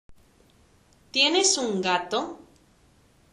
発音がゆっくりですので、あまり感じませんが